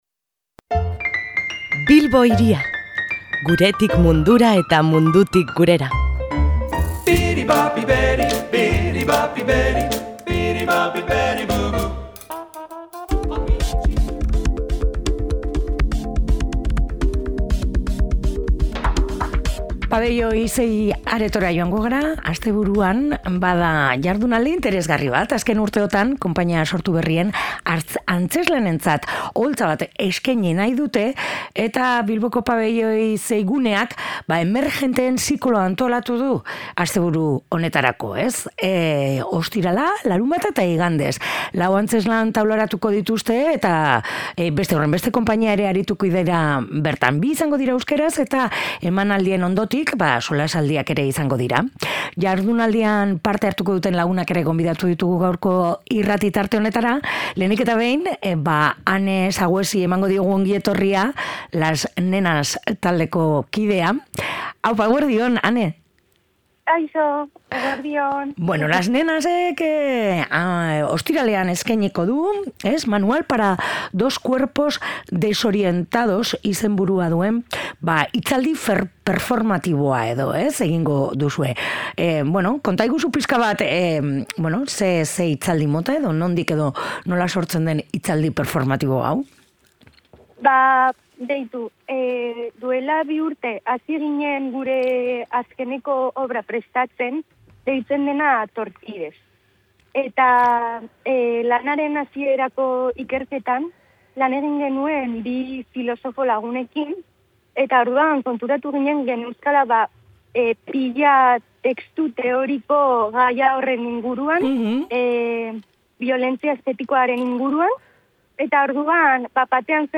Jardunaldietan parte hartuko duten bi lagun ditugu gurean.